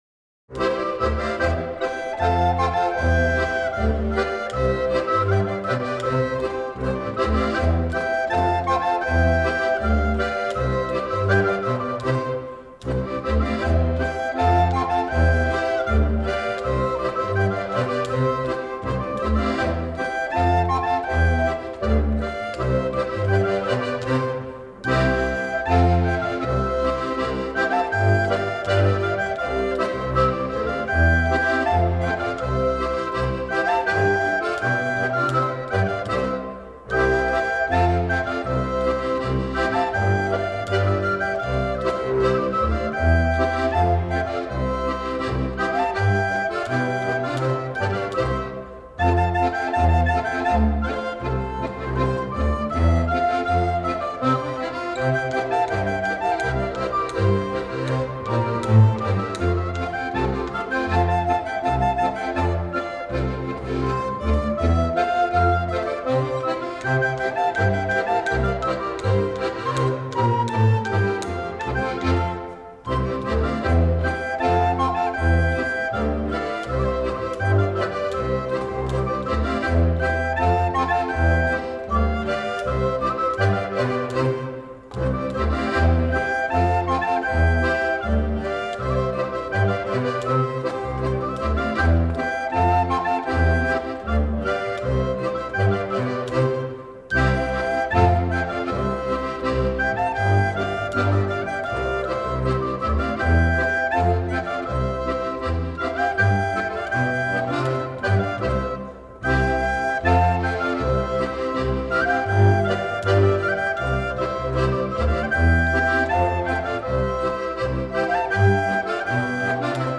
Les Genépis: Polka traditionelle de Champéry – Traditional polka from Champéry